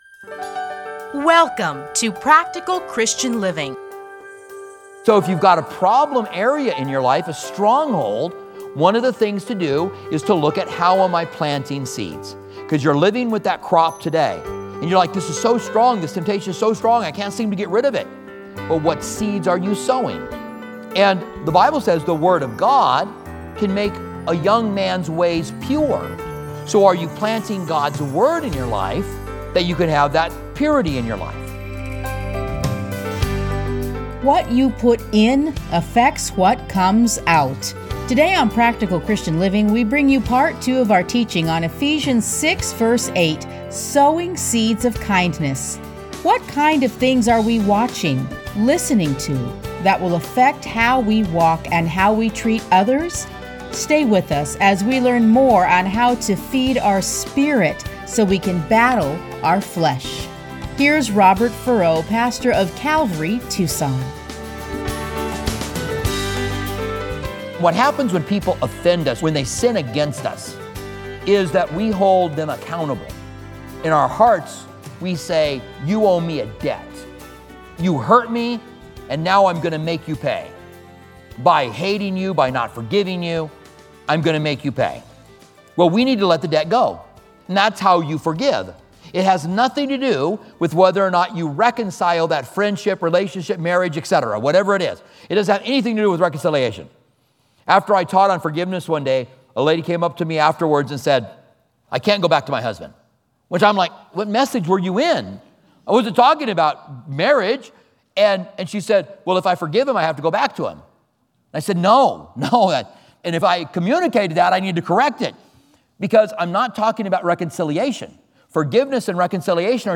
Listen to a teaching from Ephesians 6:8.